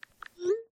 chat-send.mp3